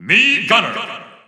The announcer saying Mii Gunner's name in English and Japanese releases of Super Smash Bros. Ultimate.
Mii_Gunner_English_Announcer_SSBU.wav